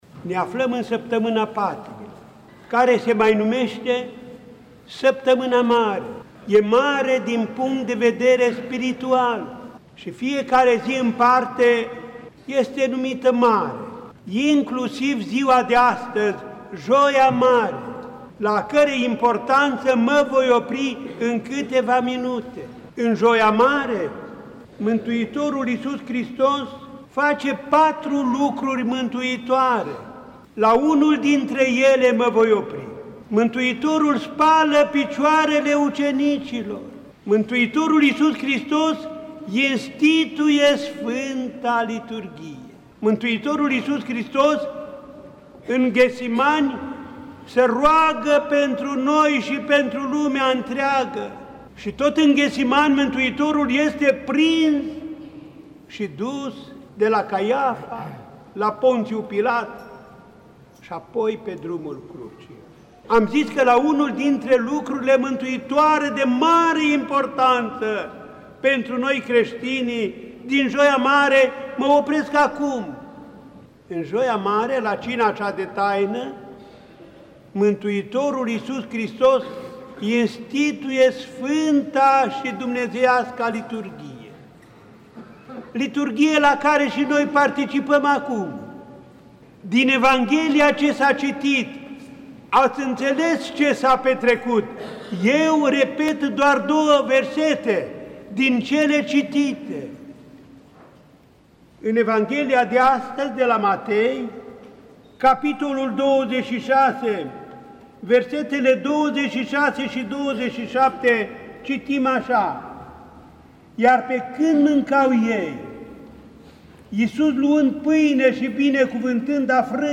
În Sfânta și Marea Joi din Săptămâna Patimilor, zi în care a fost instituită Sfânta Euharistie, la Cina cea de Taină, Mitropolitul Clujului a săvârșit Sfânta Liturghie a Sfântului Vasile cel mare unită cu Vecernia, în Catedrala Mitropolitană din Cluj-Napoca.
Înaltpreasfințitul Andrei a explicat cu acest prilej evenimentele petrecute în Sfânta și Marea zi de Joi, accentuând importanța Liturghiei și a Sfintei Euharistii: